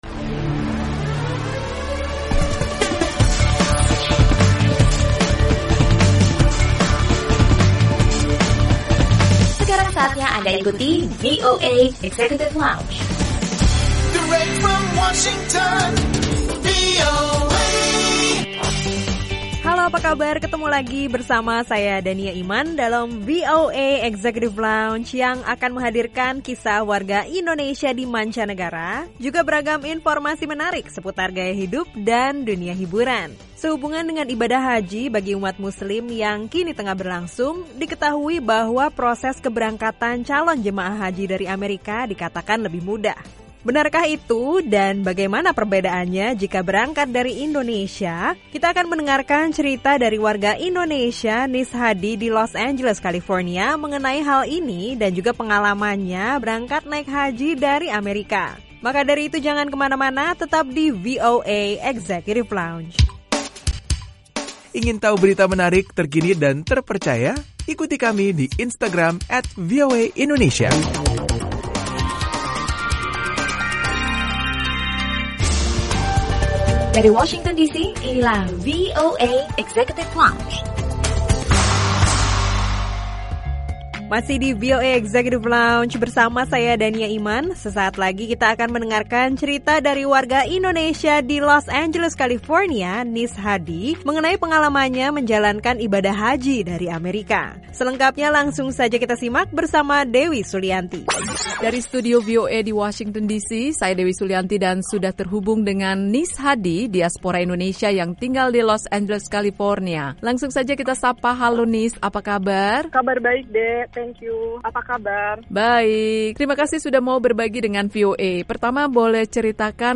Simak obrolan bersama warga Indonesia